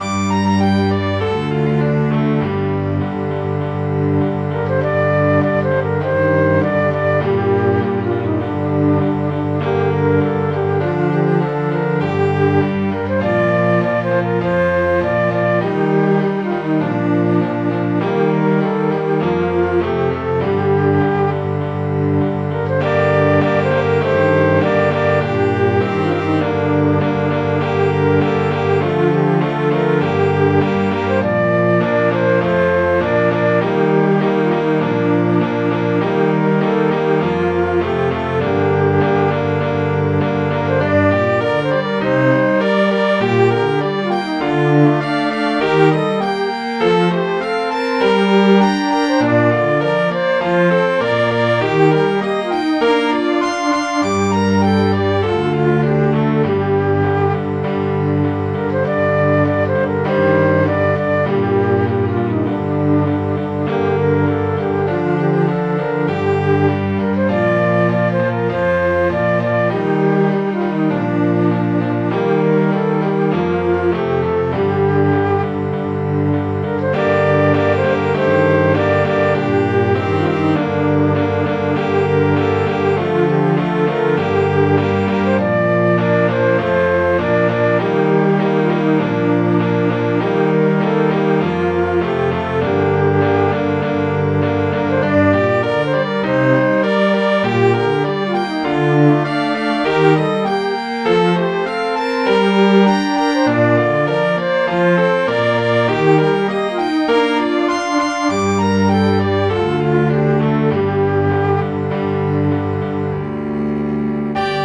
Midi File, Lyrics and Information to Fair Susan